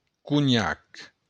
Cognac (French pronunciation: [kɔɲak] ; Saintongese: Cougnat; Occitan: Conhac [kuˈɲak]